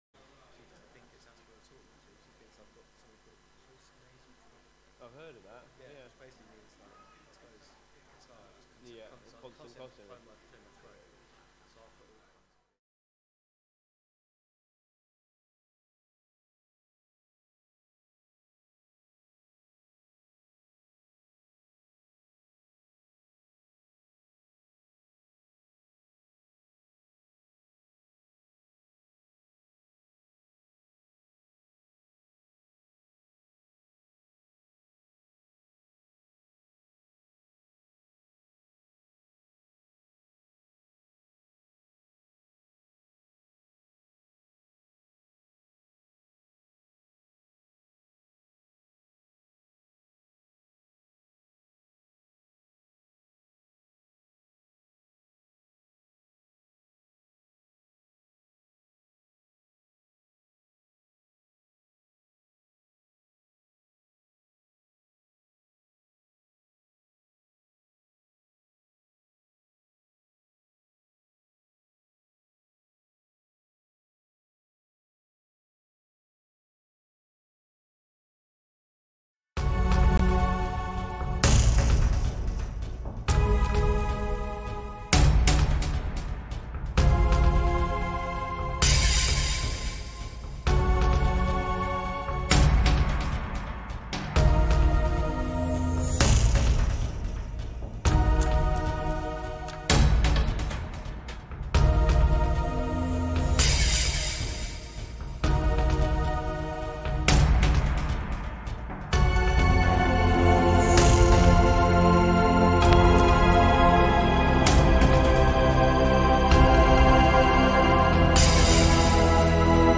Sunday 3rd July – joint service